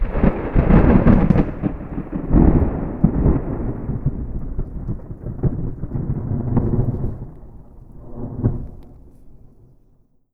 Thunder 3.wav